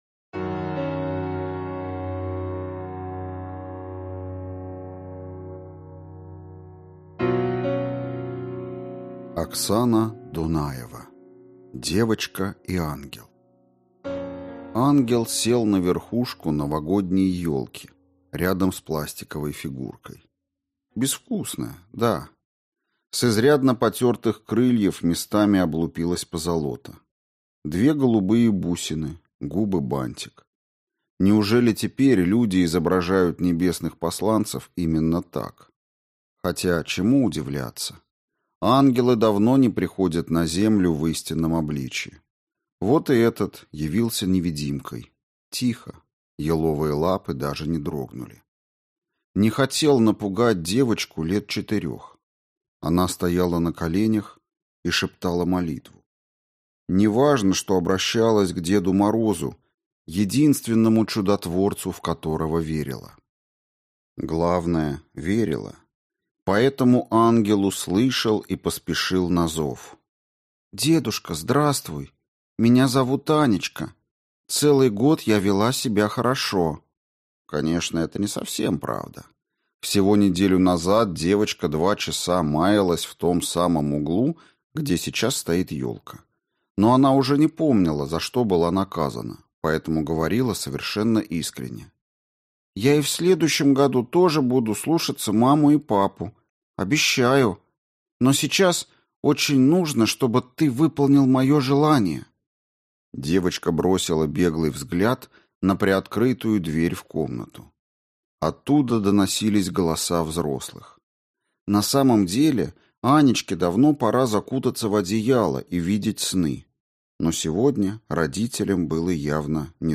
Аудиокнига «Краткость и талант». Альманах-2020 | Библиотека аудиокниг